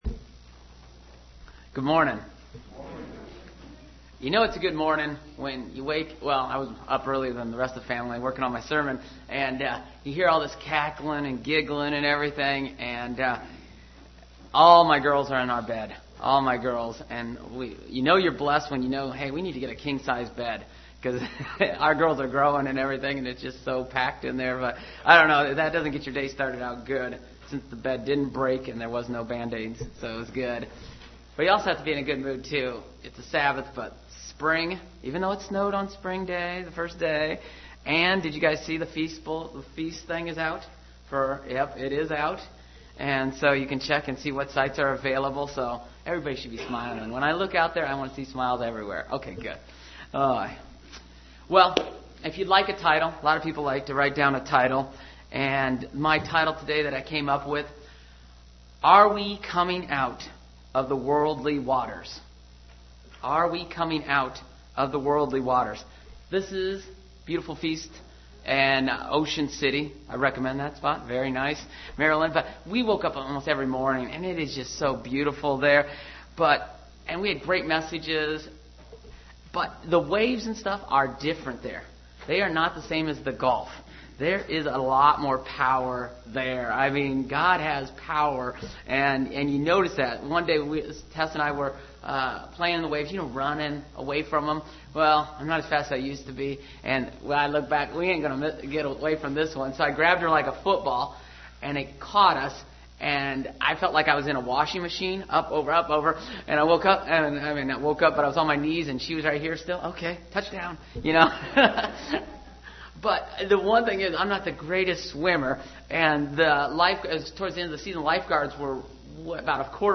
Given in Ft. Wayne, IN
UCG Sermon Studying the bible?